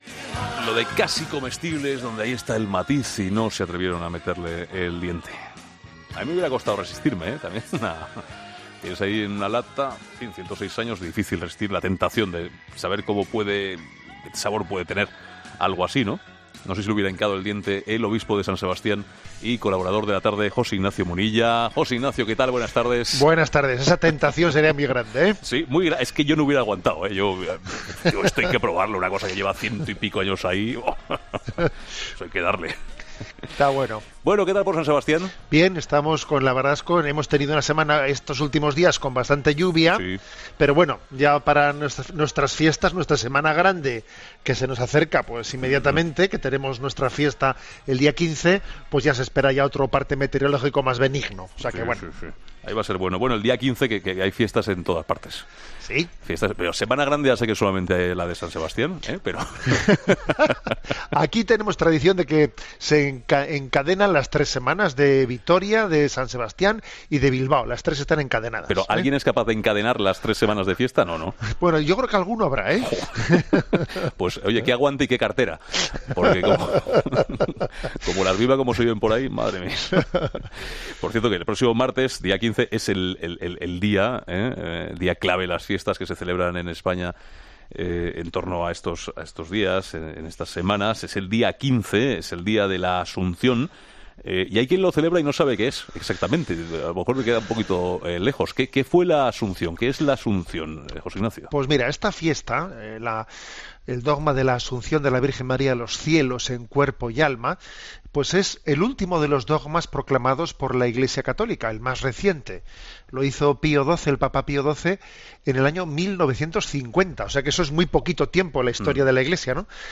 El obispo de San Sebastián, José Ignacio Munilla, vuelve a 'La Tarde' para hablar de los temas de actualidad de la semana.